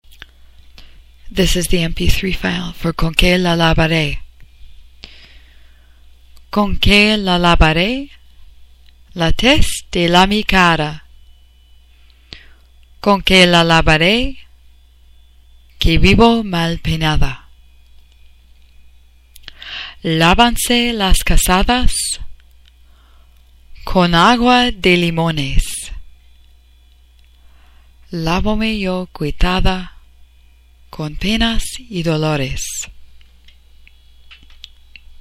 Spanish Song
Folk Songs